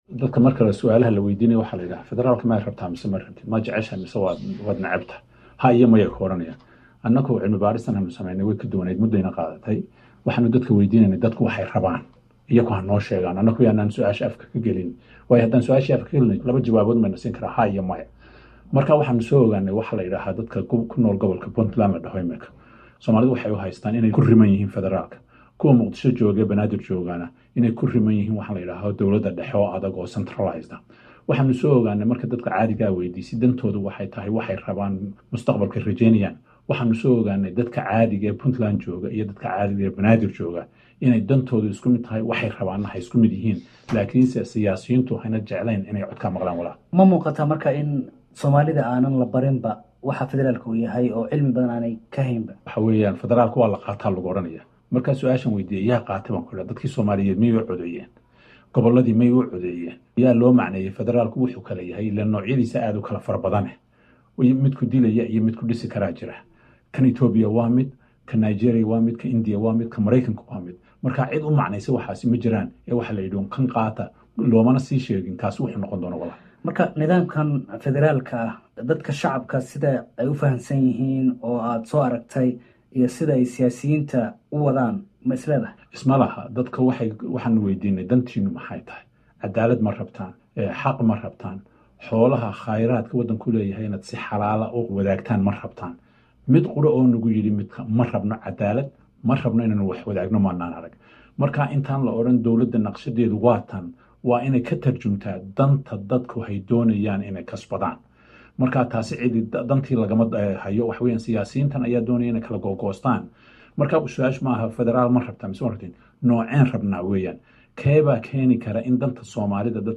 Waraysi uu bixiyay Prof Cabdi Samatar oo uu kaga hadlay daraasad ku saabsan Federaalka ayuu ku soo ogaadey in Somalidu warba u haynin waxa loogu yeero Federaalka waxa kale oo uu sheegay in Puntland oo lagu tilmaamo in ay rabto federal nooca uu shacabkeedu aqoon u lahayn waxa ay rabaan taas oo uu mid la mida ka sheegay shacabka Muqdisho degan.